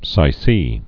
(sī-sē)